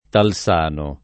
[ tal S# no ]